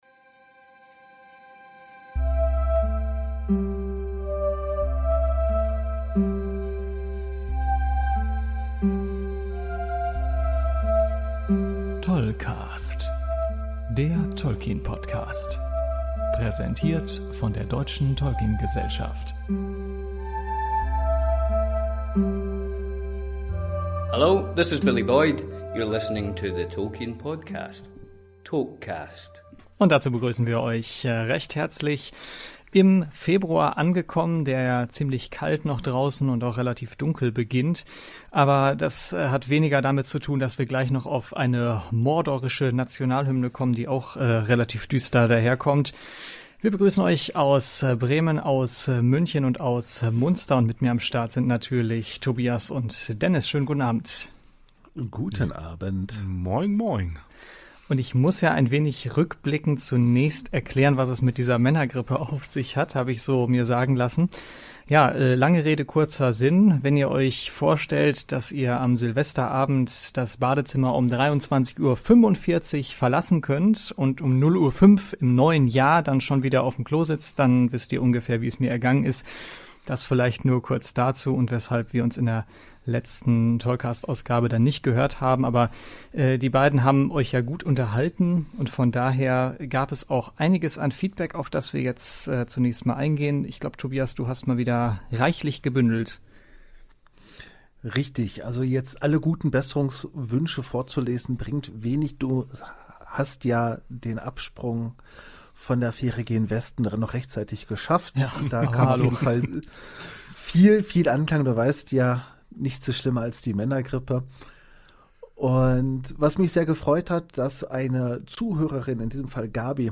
Es ist die längste und vollste Sendung geworden und sie hält sogar einen Bonus-Track bereit: ein exklusiv Interview mit Billy Boyd.